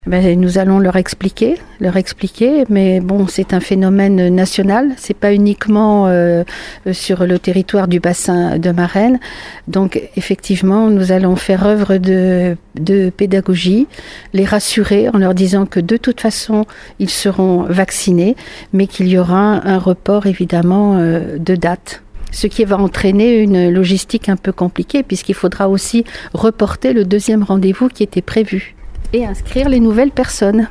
La colère de la maire de Marennes-Hiers-Brouage contre le manque de vaccins anti-covid.